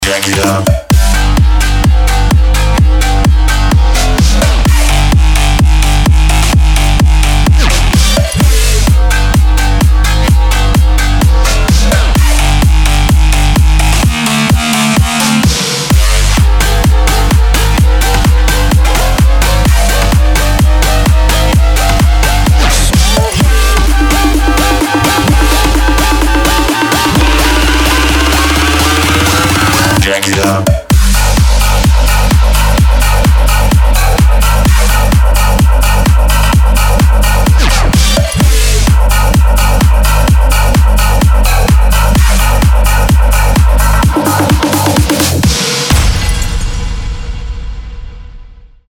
Electro House / Jackin House